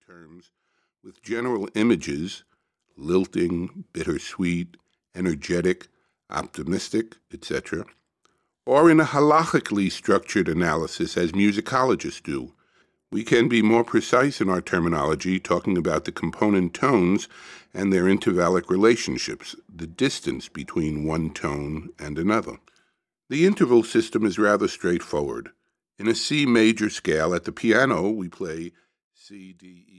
(Audiobook)